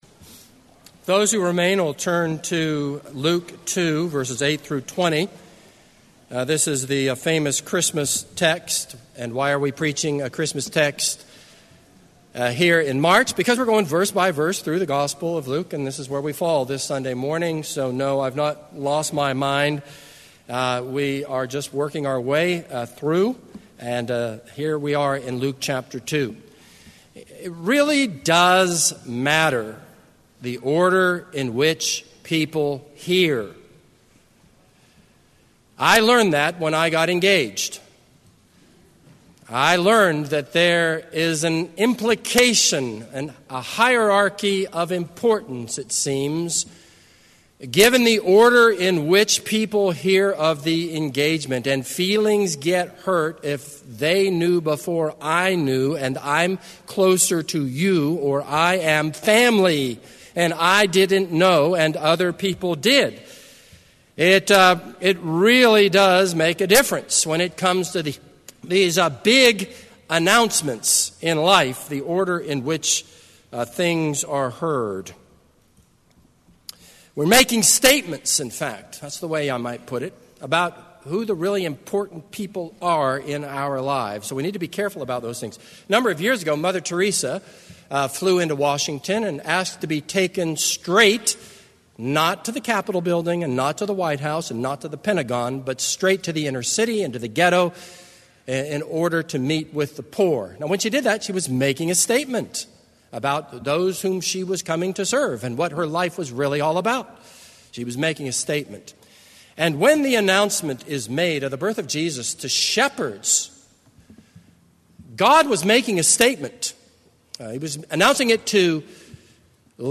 This is a sermon on Luke 2:8-20.